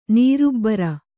♪ nīrubbara